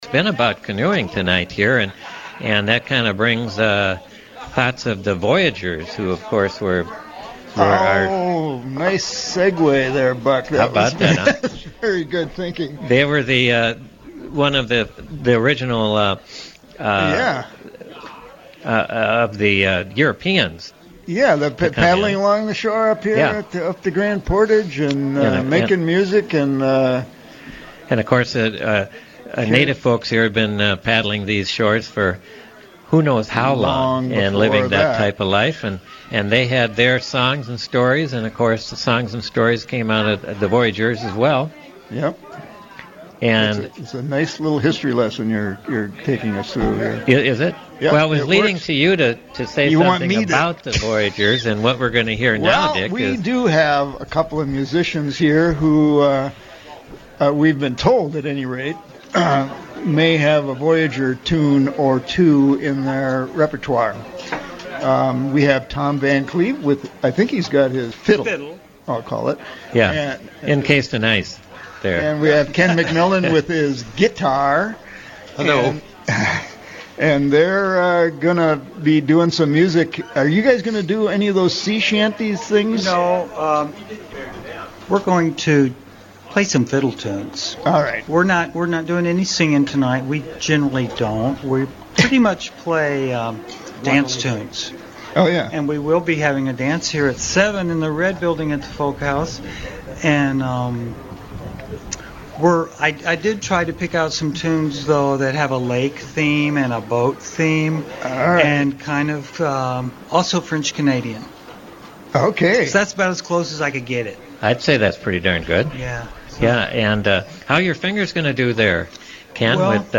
French Voyageur music live from the Wooden Boat Show at North House
fiddle
guitar) braved chilly conditions
toe-tappin' music
broadcasting on-site